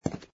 fs_fr_stone03.wav